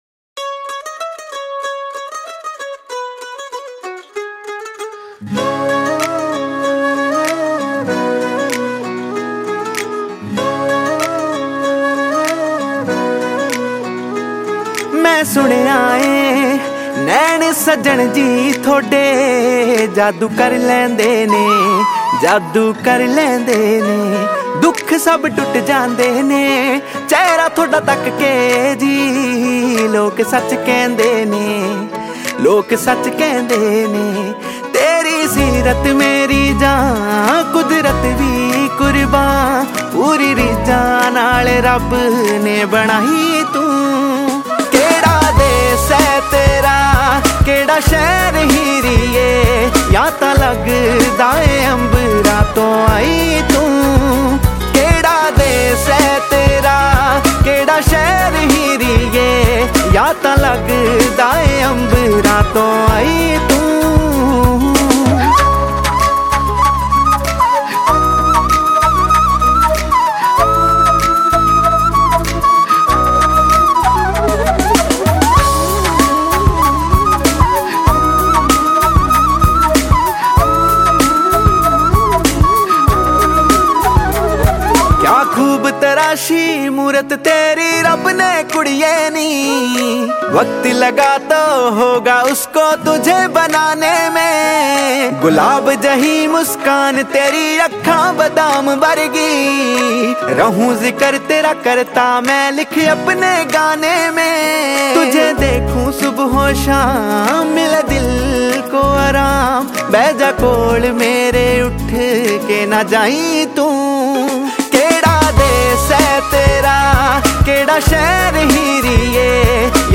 2020 Pop Mp3 Songs
Punjabi Bhangra MP3 Songs